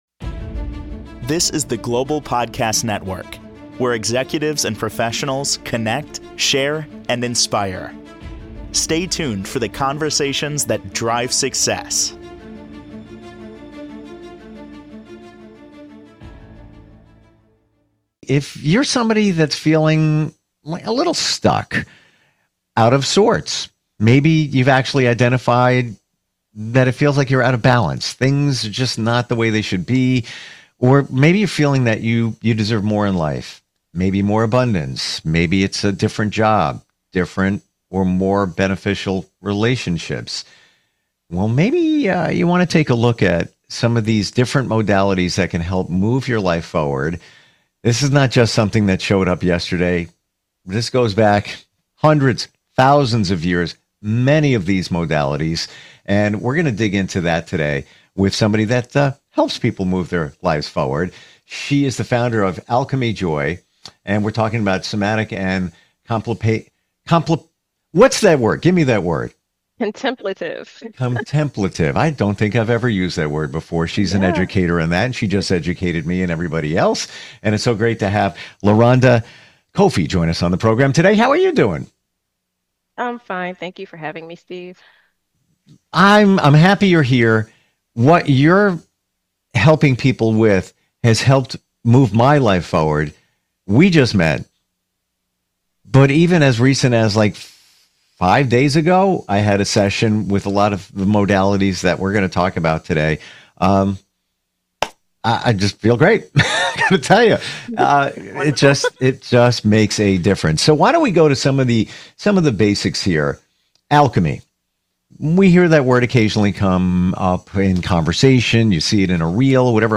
Podcast Conversation: Nervous System & Breathwork